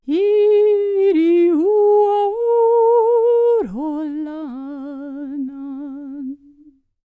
K CELTIC 30.wav